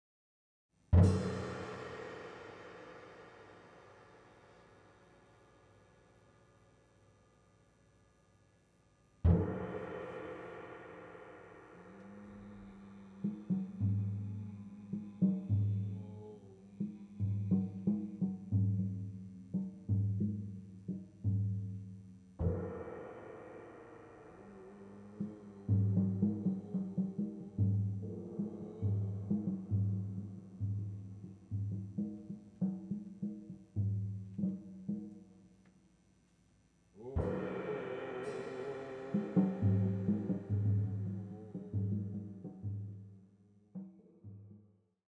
Recorded on at Jazz Spot Candy
いつものように何も決めずに互いに刺激し合い自由にその場で曲を創り上げる。